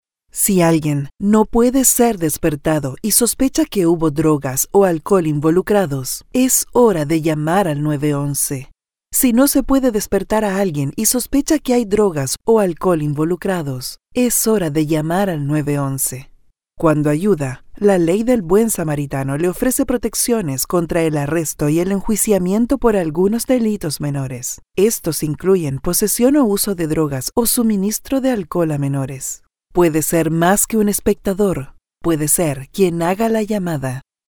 Political Ads
I have a versatile and clear Voice in Universal Neutral Spanish.
Young adult or adult female voice with a perfect diction, believable.
I own a professional audio recording studio, with soundproof booth included.